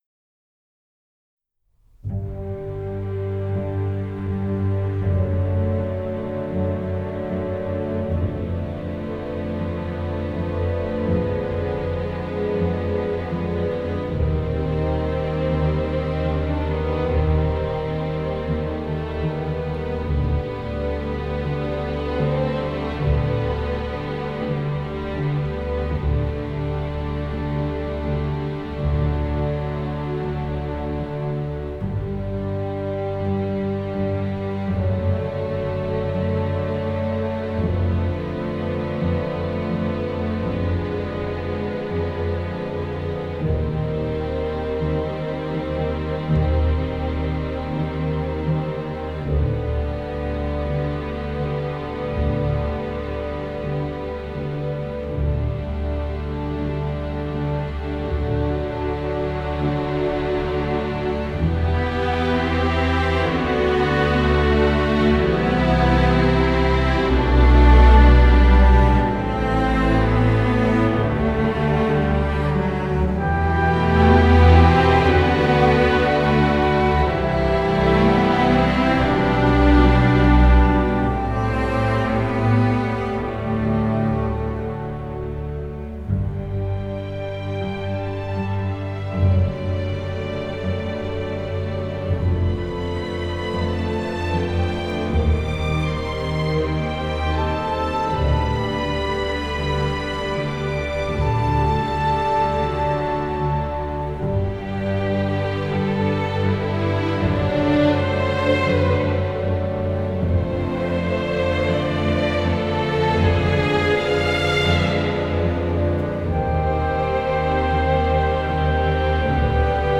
Genre : Ambient, Enigmatic, New Age